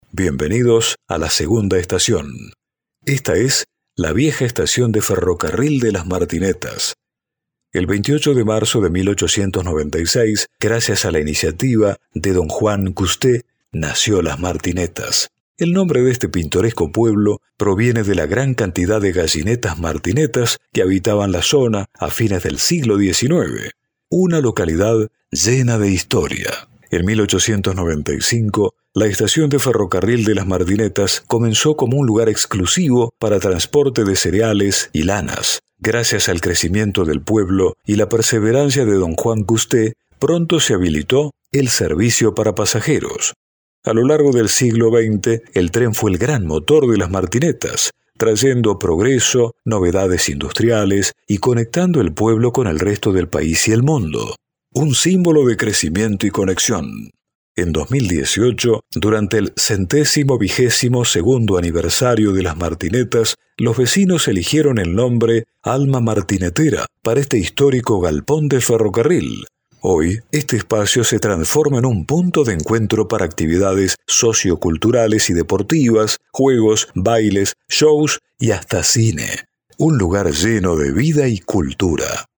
AUDIO-GUIA-MARTINETAS-ESTACION-DOS.mp3